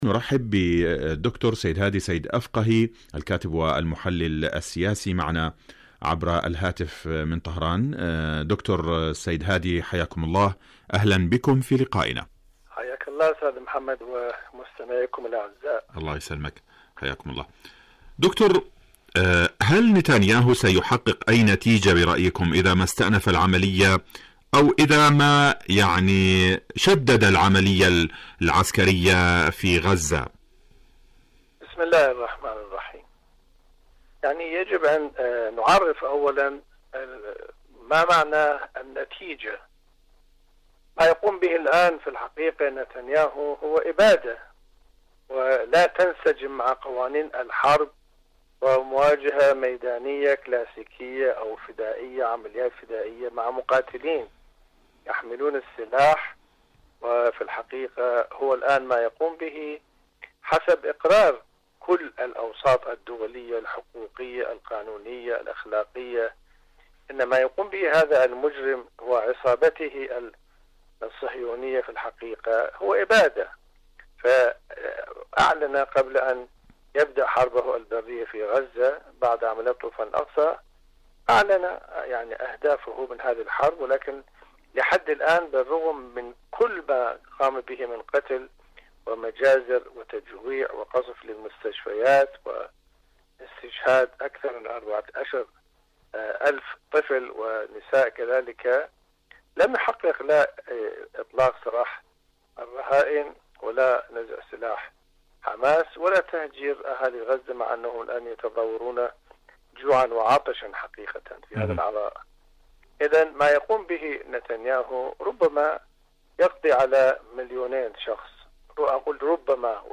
برنامج حدث وحوار مقابلات إذاعية نتنياهو ومحاولات الخلاص من المحاكمة